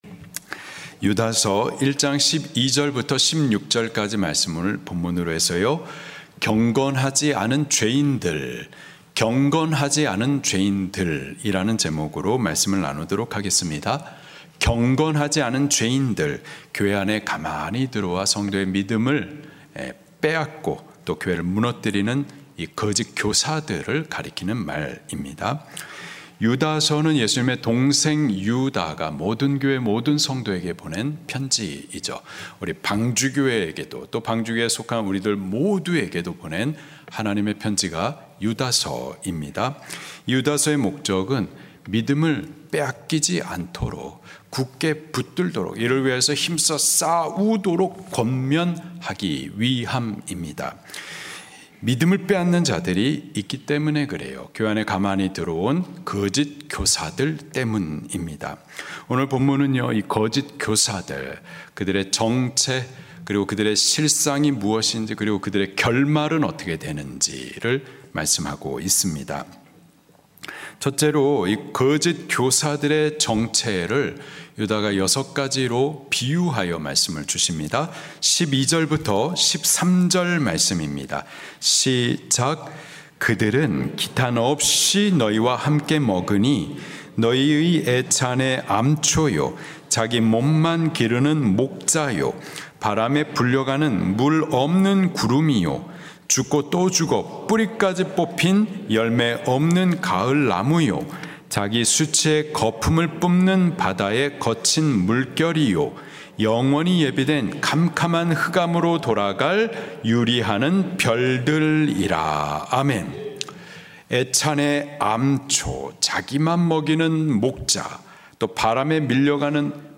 설교
주일예배